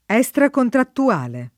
vai all'elenco alfabetico delle voci ingrandisci il carattere 100% rimpicciolisci il carattere stampa invia tramite posta elettronica codividi su Facebook estracontrattuale [ HS trakontrattu- # le ] → extracontrattuale